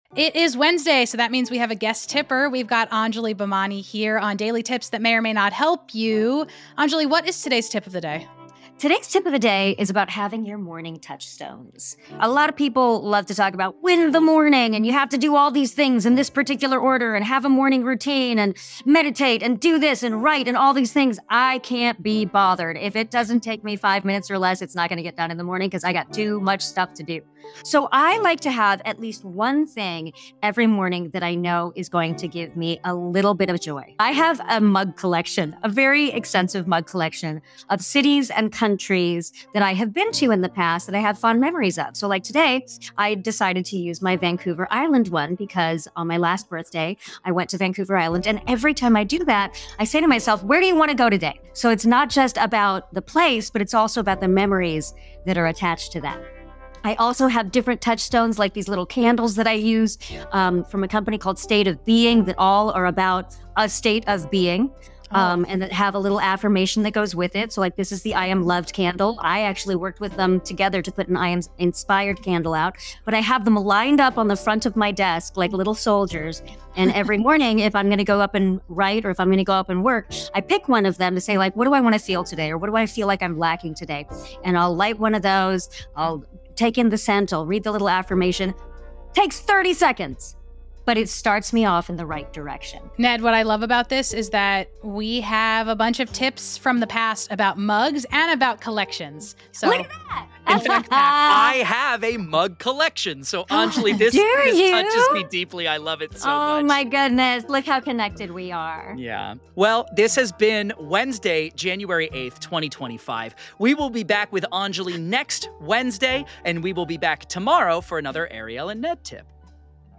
Guest Tipper Anjali Bhimani is back with another start-of-the-year grounding tip. Listen in for a new framework around touchstones and setting goals.